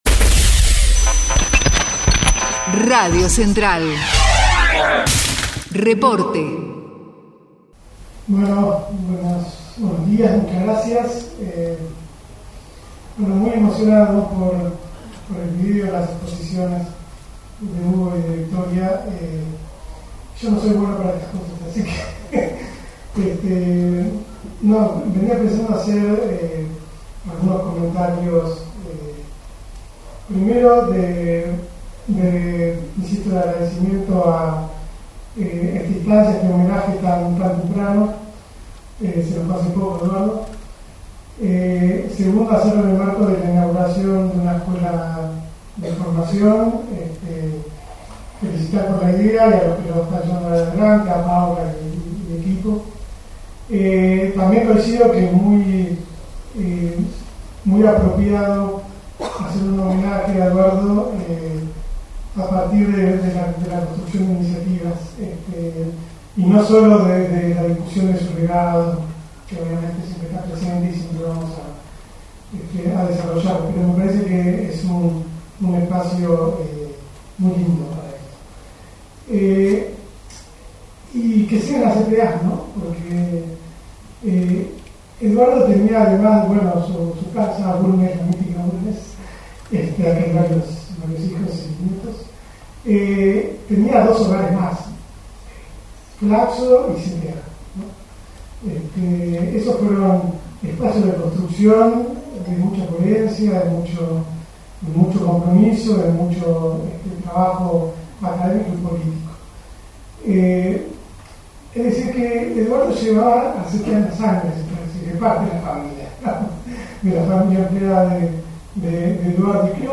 ESCUELA DE FORMACIÓN SINDICAL "EDUARDO BASUALDO" - Testimonio